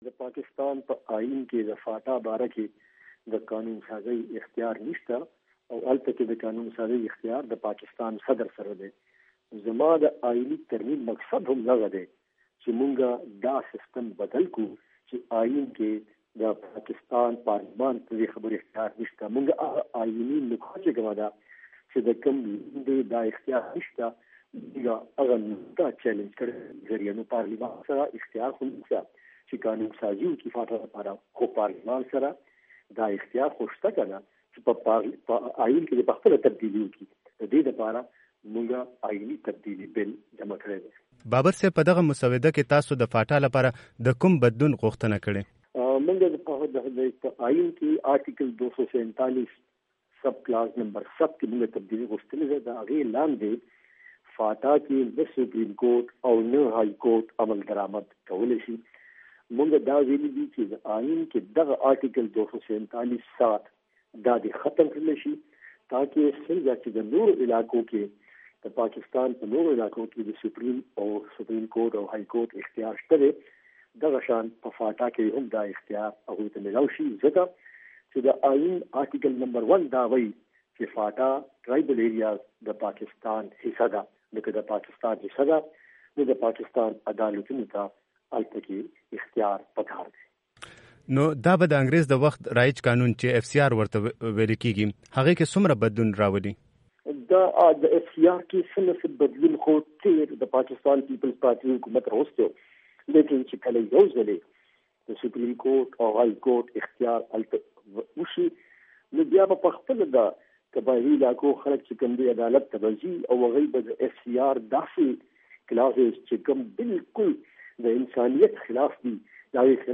وي او ای ډیوه سره مرکه کې سنیټر فرحت الله بابر وویل ددې ائیني ترمیم د لارې به فاټا کې به د اصلاحاتو لاره خلاصه شي.